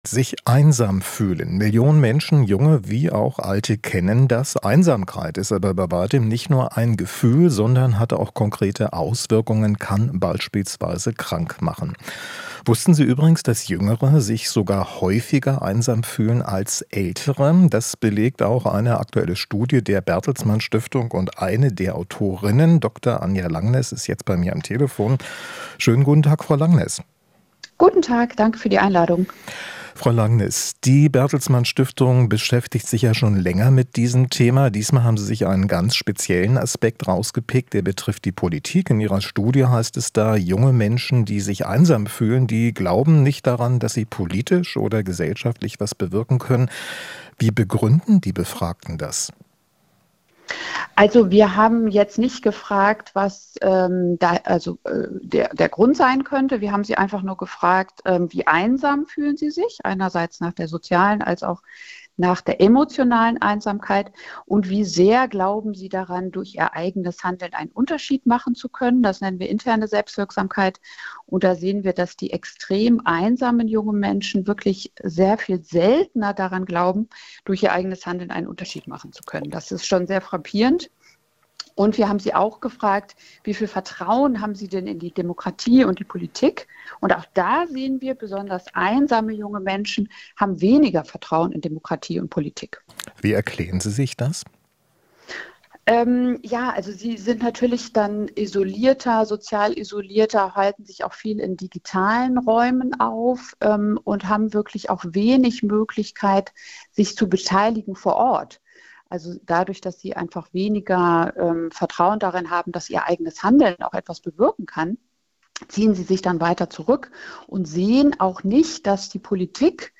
Interview - Studie: Einsame junge Menschen glauben nicht an ihre Selbstwirksamkeit